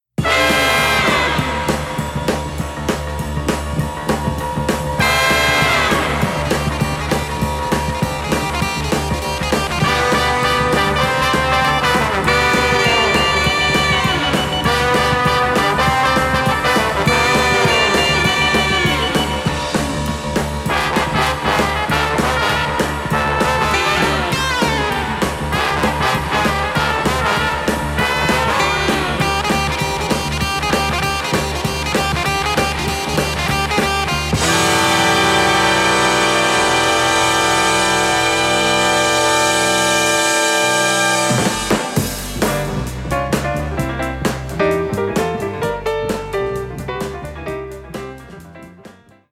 Der actionreiche, poppige Score